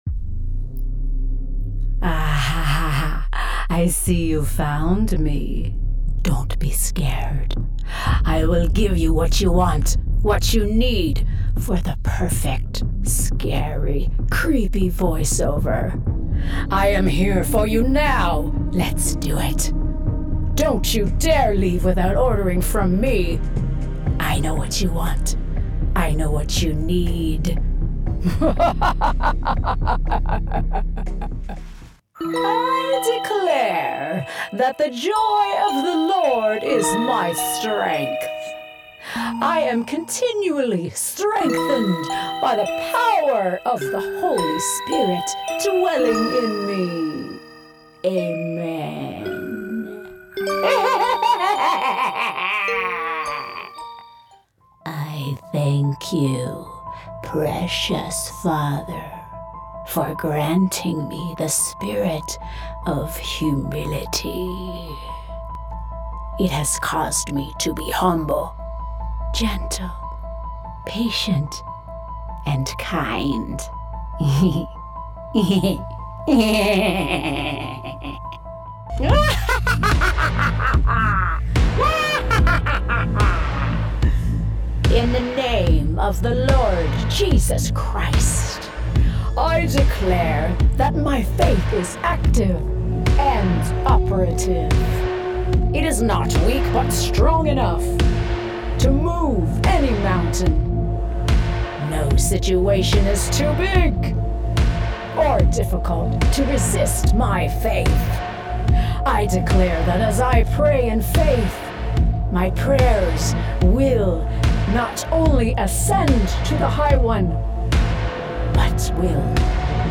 Never any Artificial Voices used, unlike other sites.
Female
Adult (30-50), Older Sound (50+)
Character / Cartoon
Evil Deep Halloween